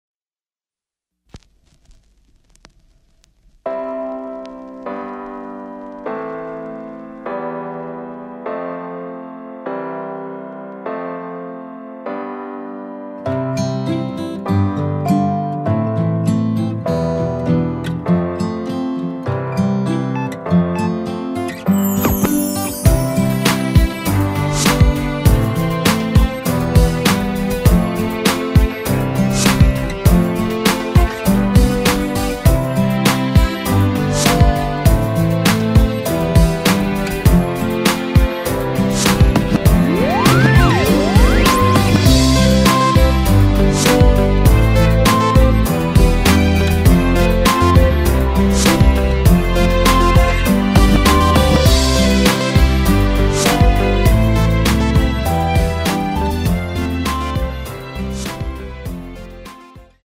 공식 음원 MR
앞부분30초, 뒷부분30초씩 편집해서 올려 드리고 있습니다.
중간에 음이 끈어지고 다시 나오는 이유는